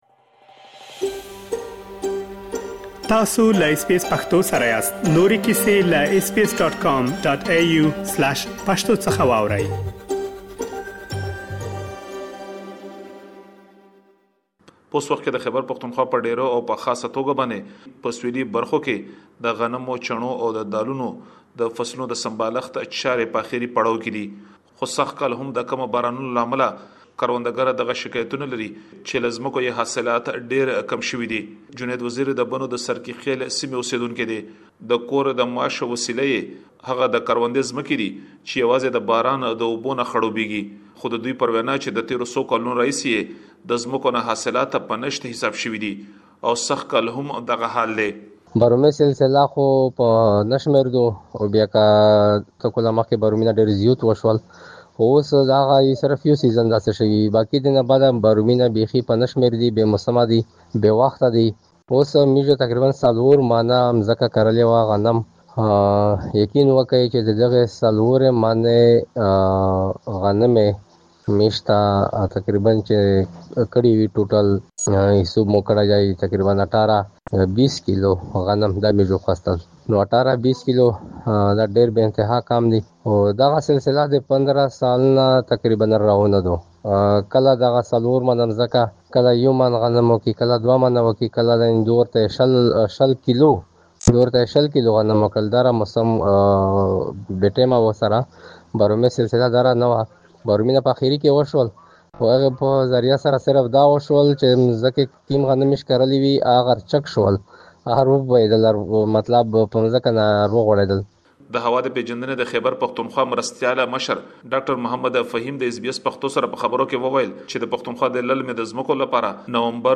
په خیبر پښتونخوا کې، د کم باران له امله د للمي ځمکو حاصلات له ۷۰ څخه تر ۸۰ سلنې پورې کم شوي دي. تاسو کولی شئ په دې اړه نور معلومات دلته په راپور کې واورئ.